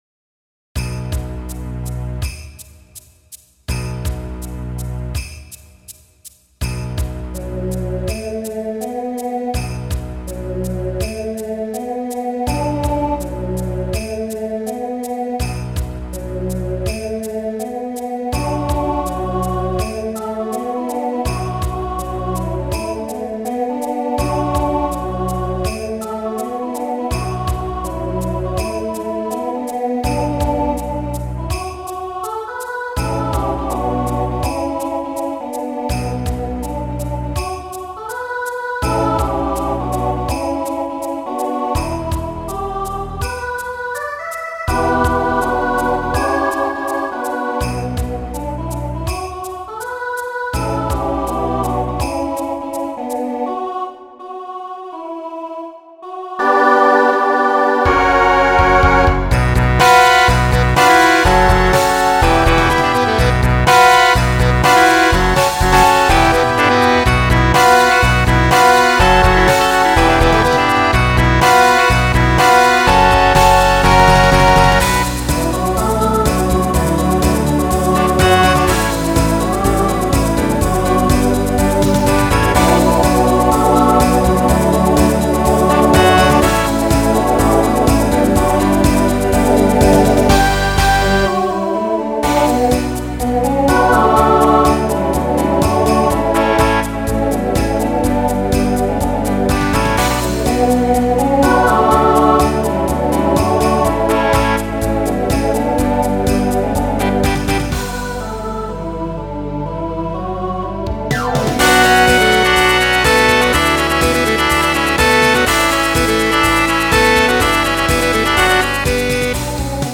Voicing SATB Instrumental combo Genre Folk , Rock